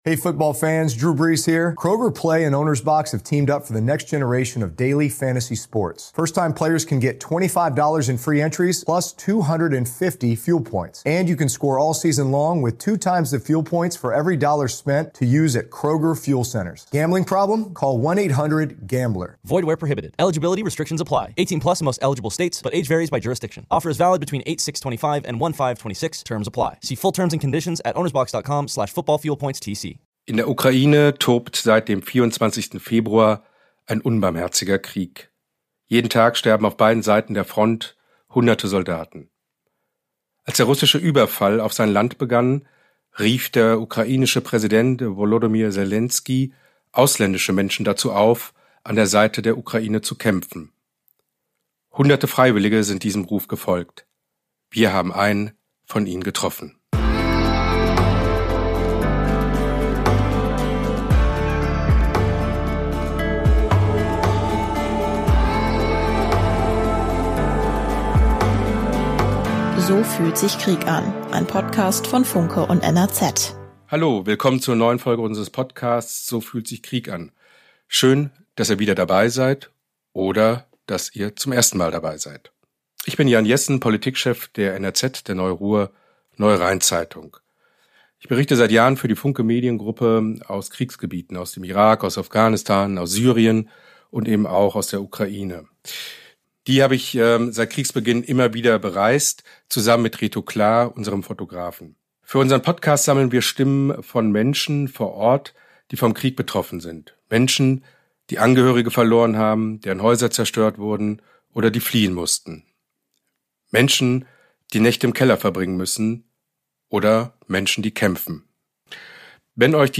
Das komplette Interview und weitere Hintergründe zu Legionären im Krieg hört ihr in dieser Folge.